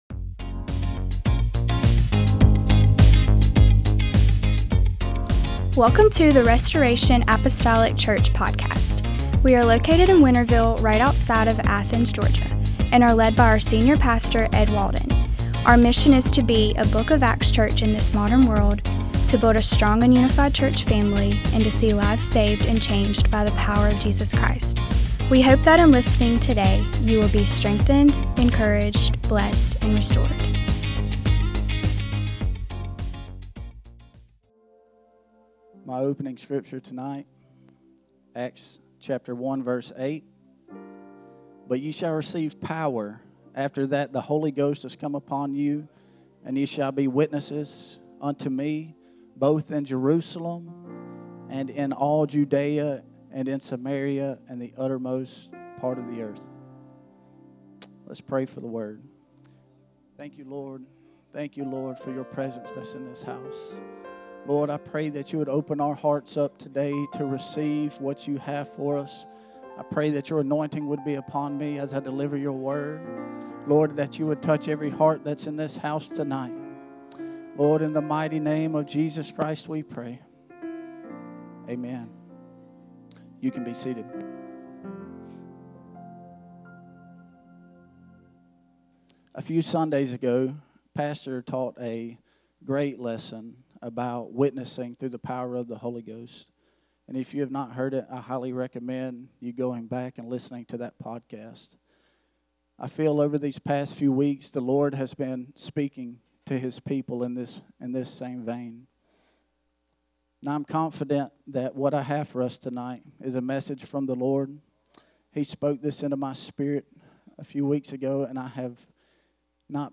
MDWK Service